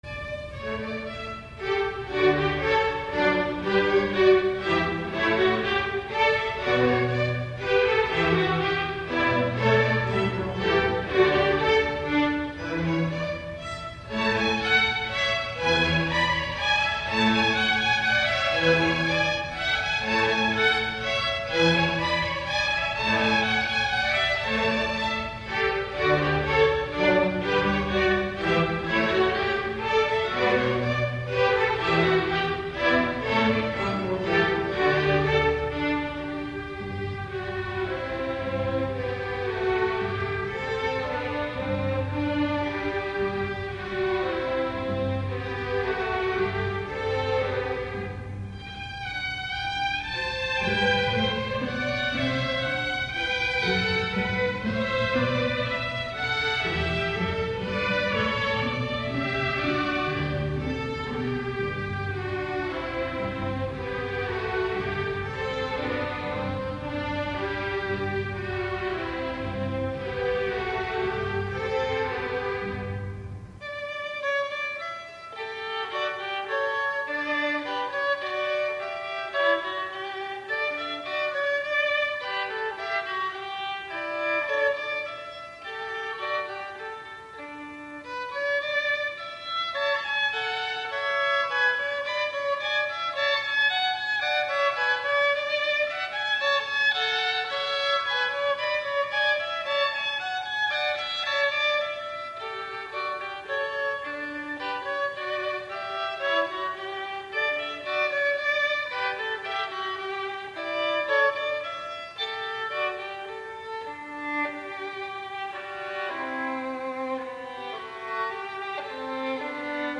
qui joue les deux parties du canon en doubles cordes.